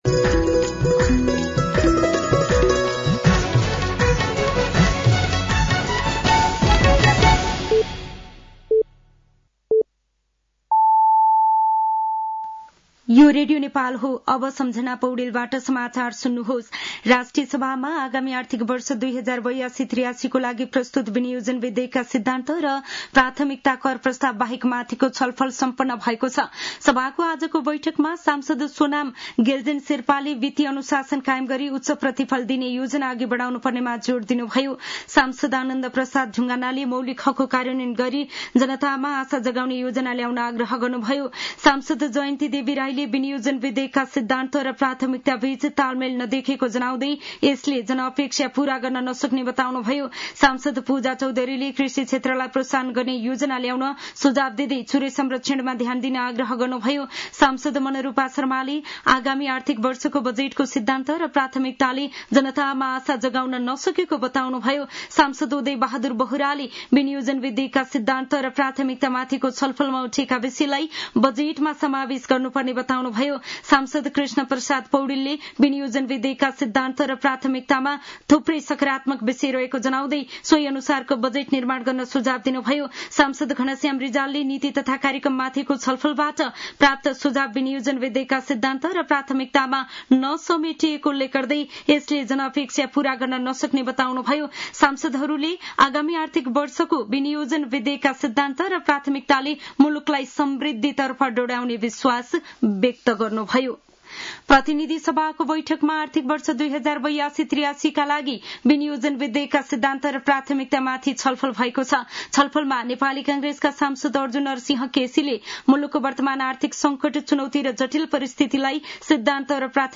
साँझ ५ बजेको नेपाली समाचार : ३१ वैशाख , २०८२